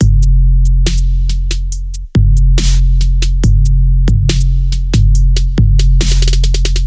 陷阱节拍
Tag: 140 bpm Trap Loops Drum Loops 1.16 MB wav Key : Unknown